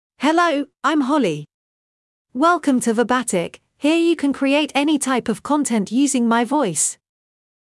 FemaleEnglish (United Kingdom)
HollieFemale English AI voice
Hollie is a female AI voice for English (United Kingdom).
Voice sample
Hollie delivers clear pronunciation with authentic United Kingdom English intonation, making your content sound professionally produced.